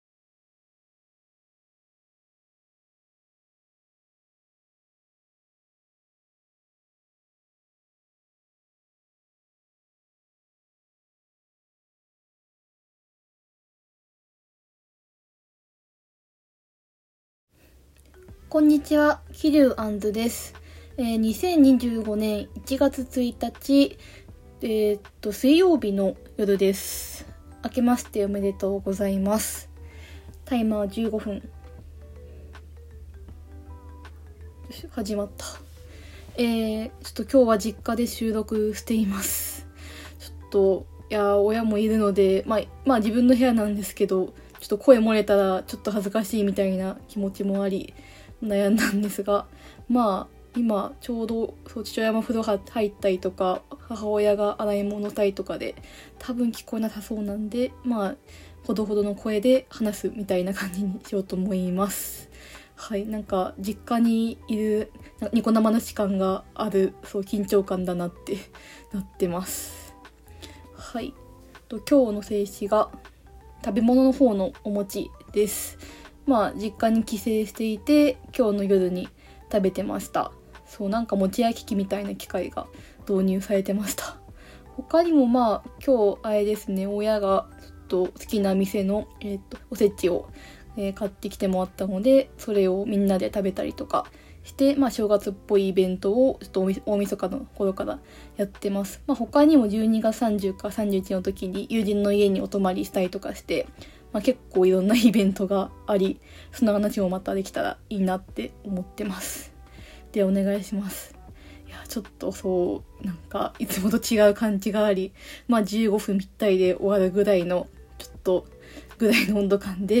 実家での収録、ラヴィット!を友人宅で見た話、紅白歌合戦のB'zについてなどを話しています。